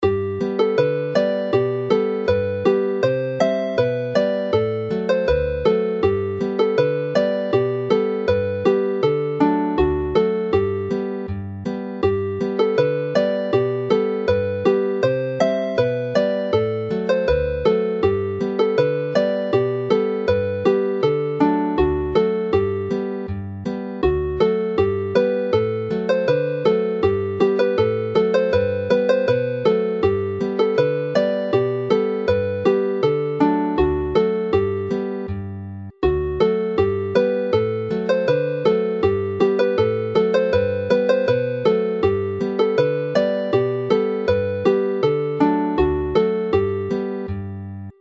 The lyrical Mwynen Merthyr is clearly from the South; Hufen Melyn is a familiar and catchy melody which does not fit into normal dance tune patterns, whilst Gwreiddyn y Pren Ffawydd is a standard 16-bar 2A 2B.
Play the tune slowly